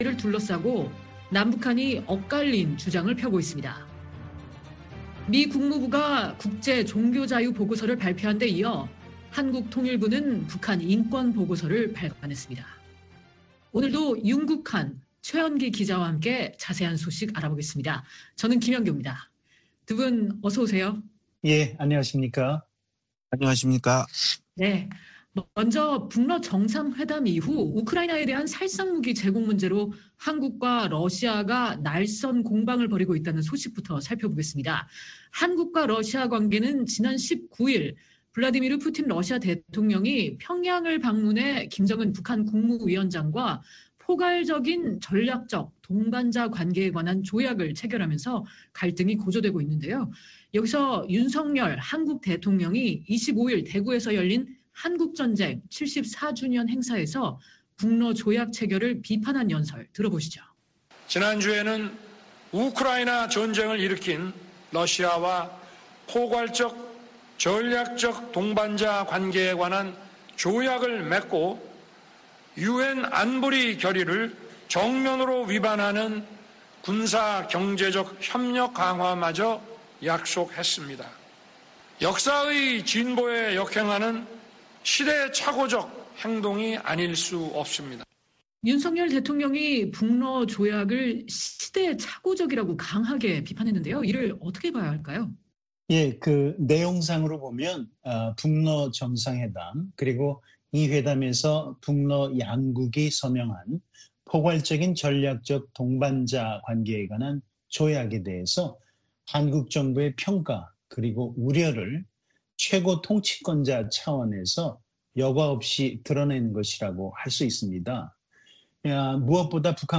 VOA 한국어 방송의 월요일 오전 프로그램 2부입니다. 한반도 시간 오전 5:00 부터 6:00 까지 방송됩니다.